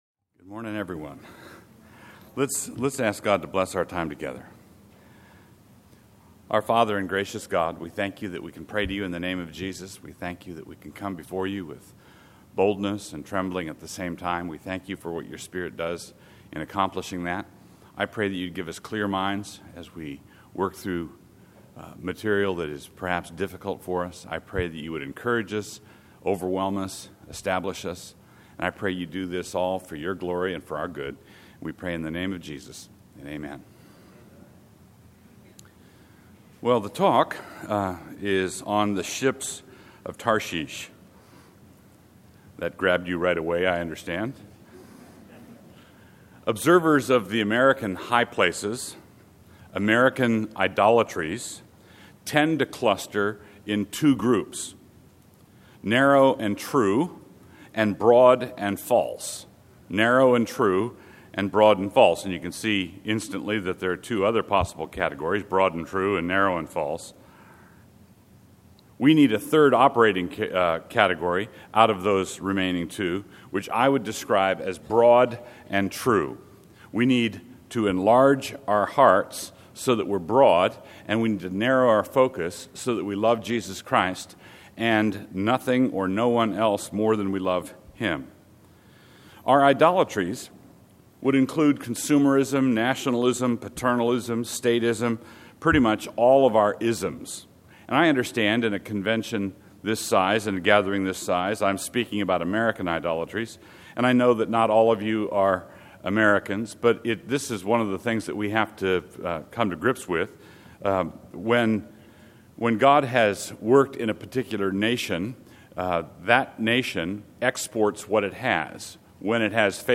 2012 Workshop Talk | 0:59:22 | All Grade Levels, Culture & Faith
Additional Materials The Association of Classical & Christian Schools presents Repairing the Ruins, the ACCS annual conference, copyright ACCS.